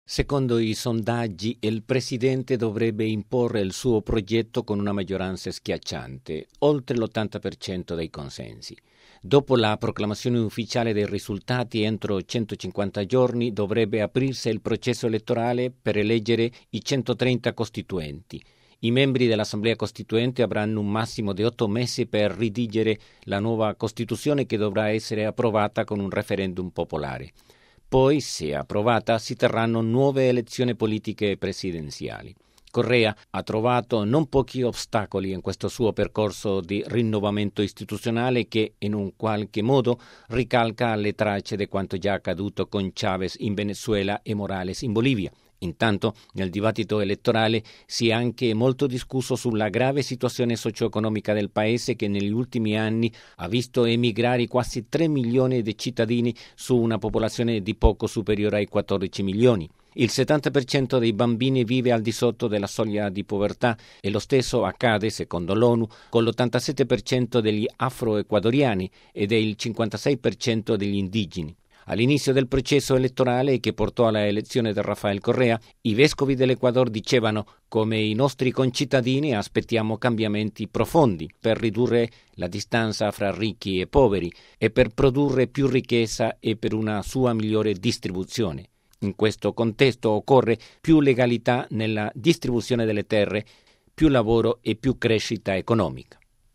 Il servizio